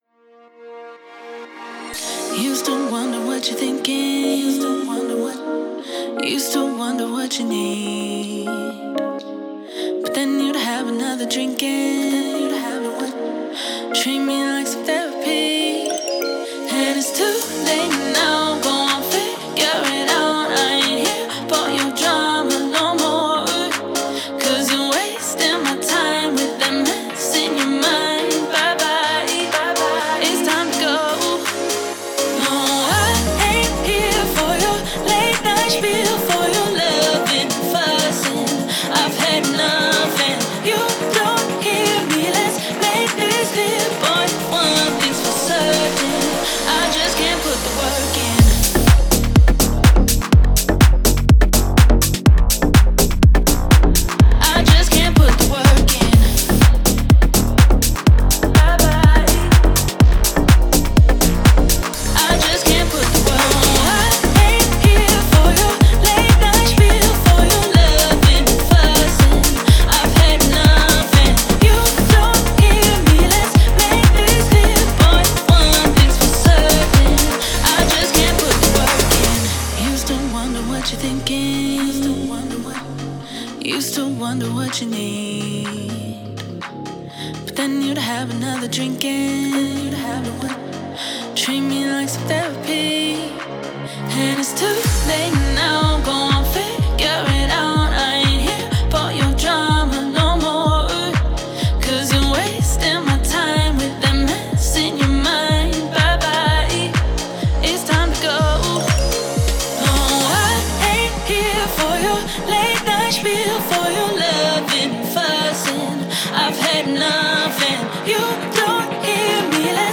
это атмосферная и меланхоличная композиция в жанре инди-поп.